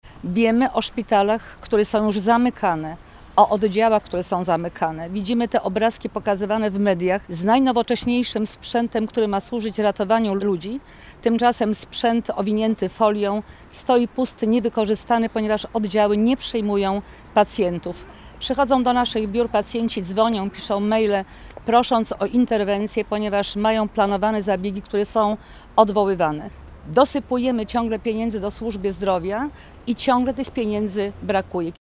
Wiceprezes PiS Elżbieta Witek, a także posłowie Paweł Hreniak, Jacek Świat oraz radni Sejmiku Województwa Dolnośląskiego spotkali się przy szpitalu uniwersyteckim we Wrocławiu, by zaapelować do rządu o podjęcie działań ws. ochrony i służby zdrowia.